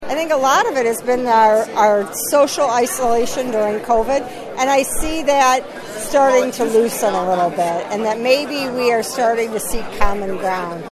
Mary K. O’Brien says more in this interview: